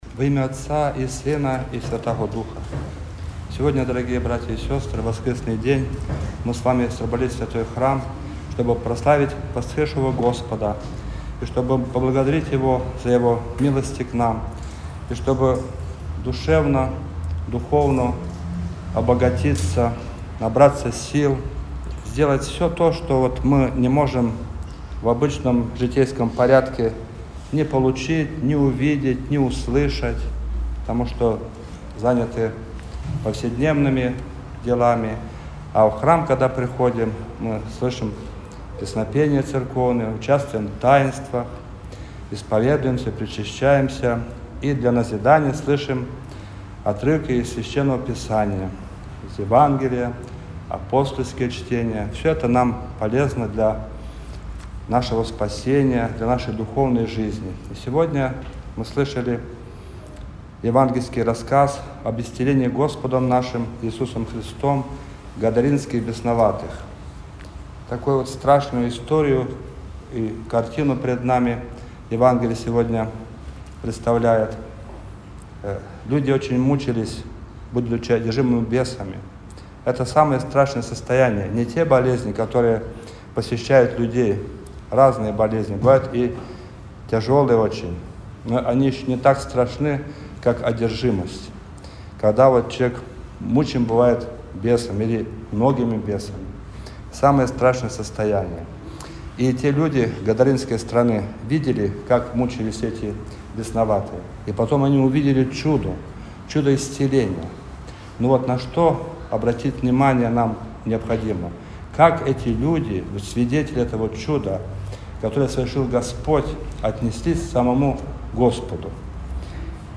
Божественную литургию в храме святого праведного Иоанна Кронштадтского на Кронштадтской площади